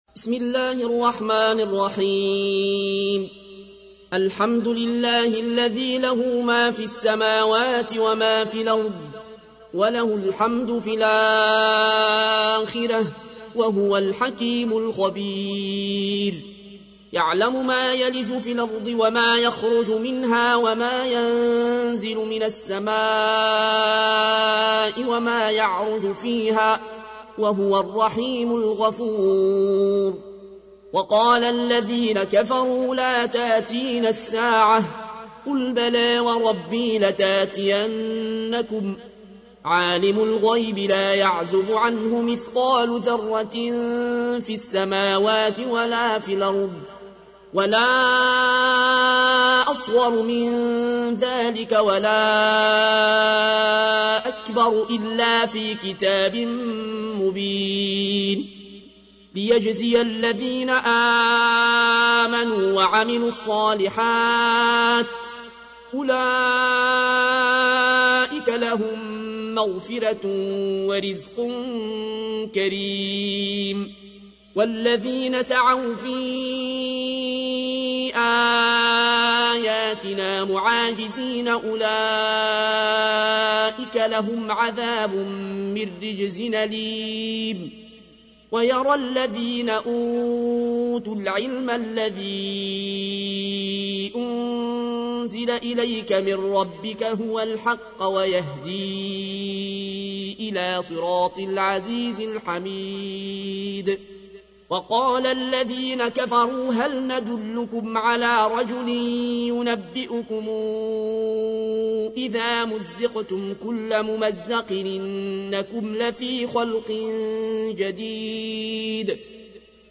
تحميل : 34. سورة سبأ / القارئ العيون الكوشي / القرآن الكريم / موقع يا حسين